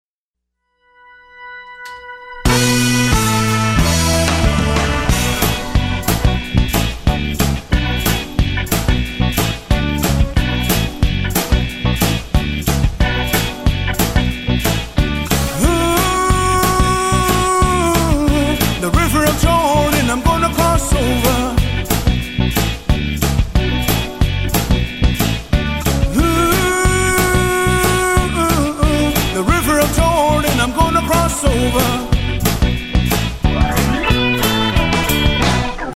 2007's Top Native Gospel Album